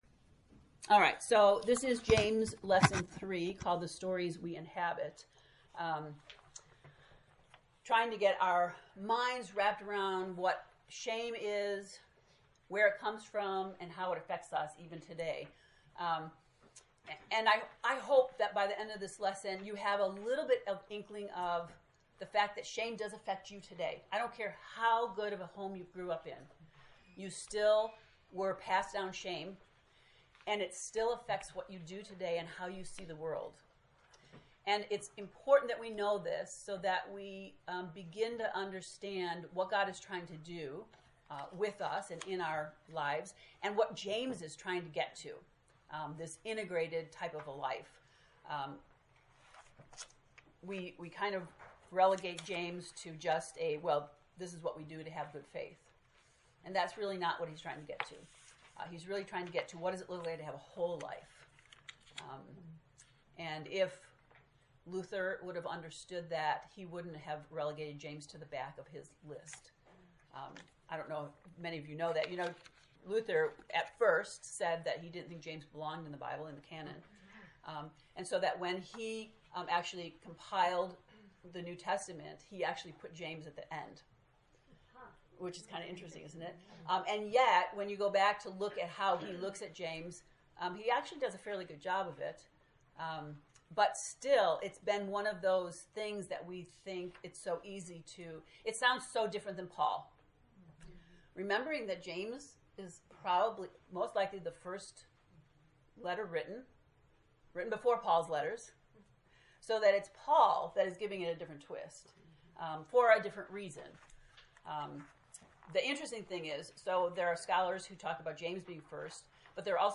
To listen to the lesson 3 lecture, “The Stories We Inhabit” click below: